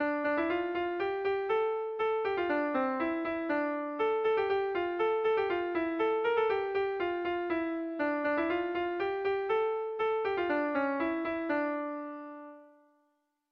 Erromantzea
ABDABB